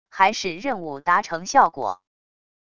还是任务达成效果wav下载